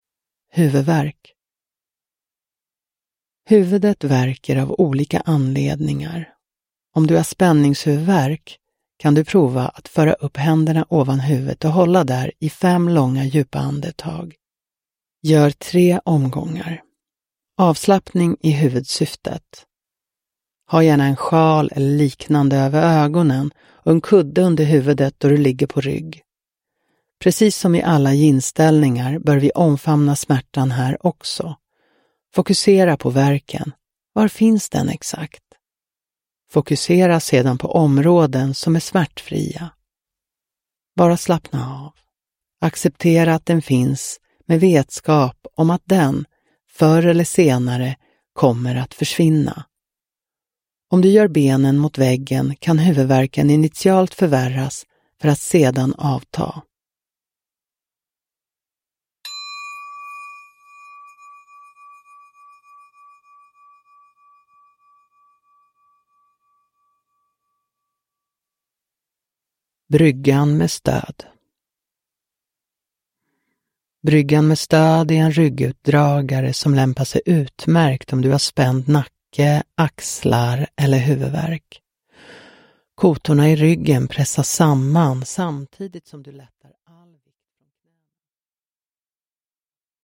Huvudvärk – Ljudbok – Laddas ner